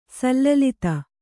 ♪ sallalita